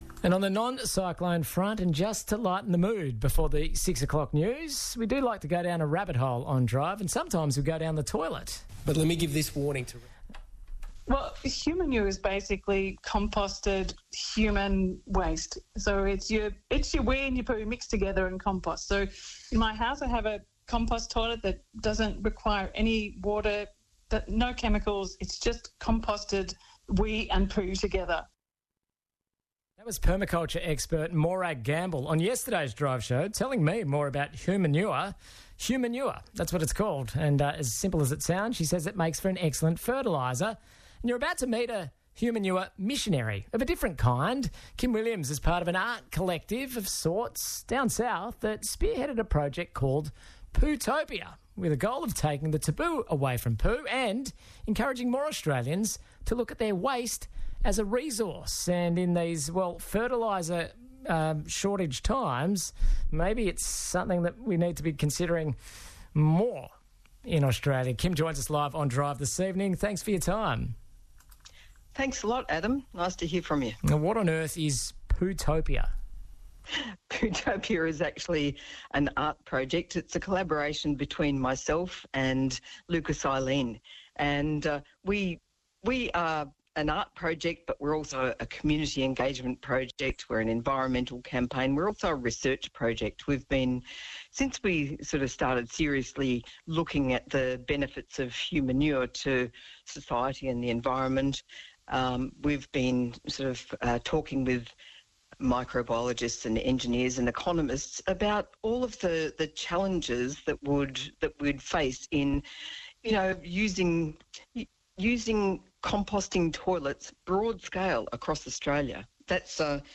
Enjoy this short and lively interview about the delights of human manure composting!